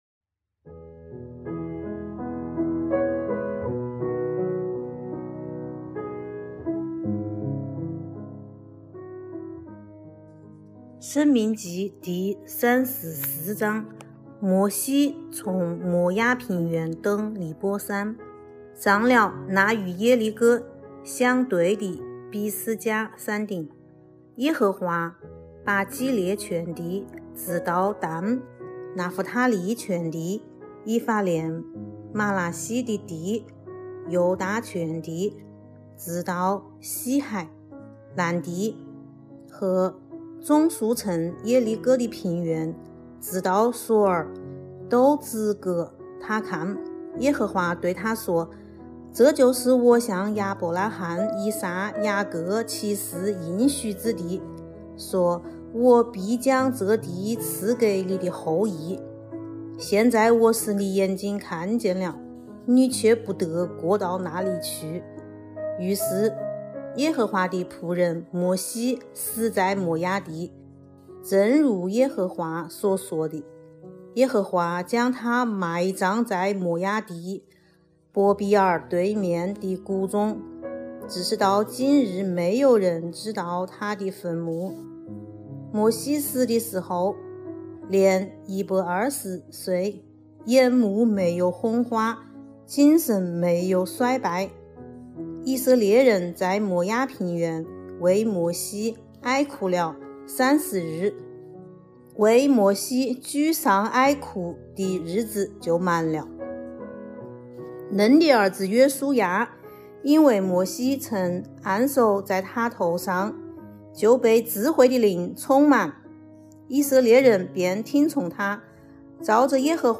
读经马拉松 | 申命记34章(武汉话)